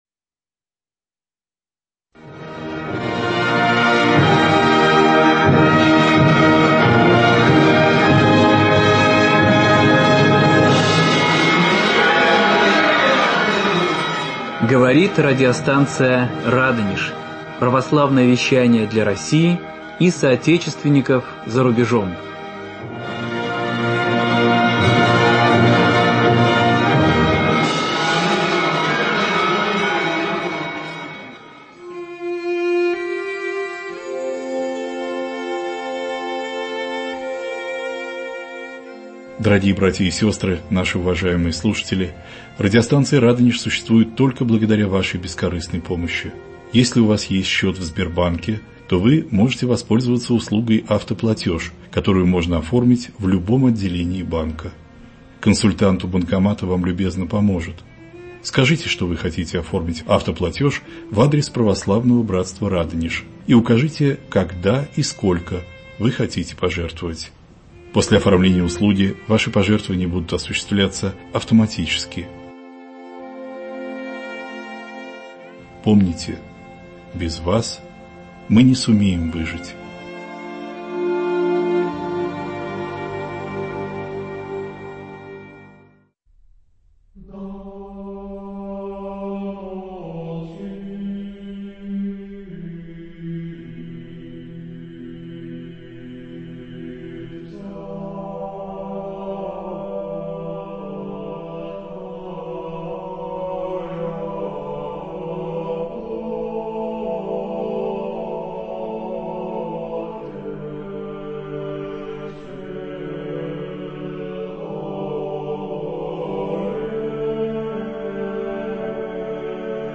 Авторская передача